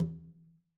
Conga-HitN_v2_rr1_Sum.wav